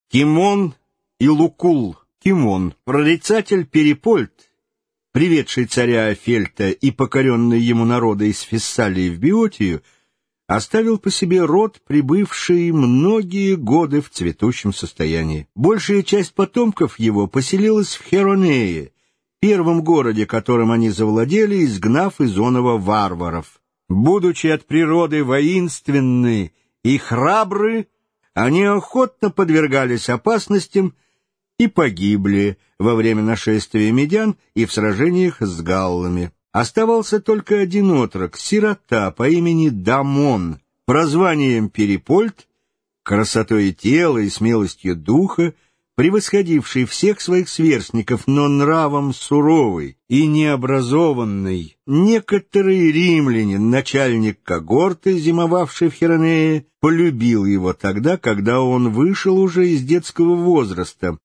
Aудиокнига Сравнительные жизнеописания.